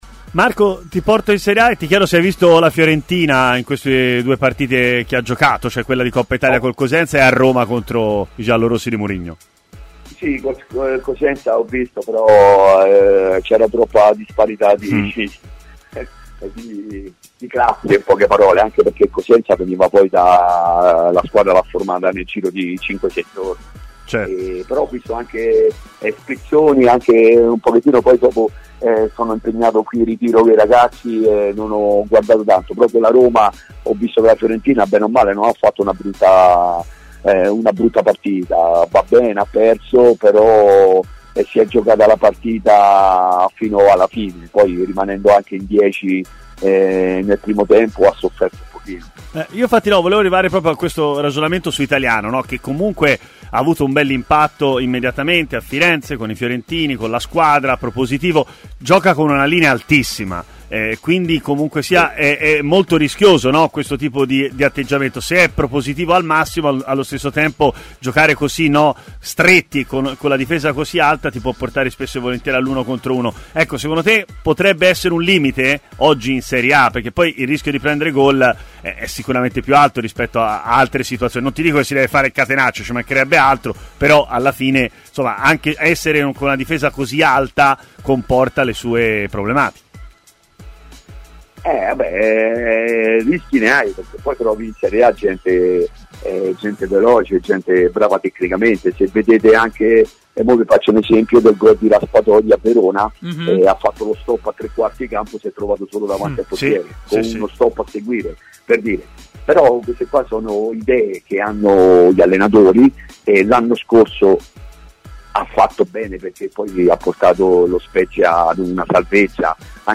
Intervenuto durante Stadio Aperto su TMW Radio, Marco Nappi, ex viola, ha parlato così della Fiorentina: